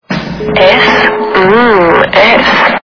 Женский голос - С-ммм-с Звук Звуки Жіночій голос - С-ммм-с
» Звуки » звуки для СМС » Женский голос - С-ммм-с
При прослушивании Женский голос - С-ммм-с качество понижено и присутствуют гудки.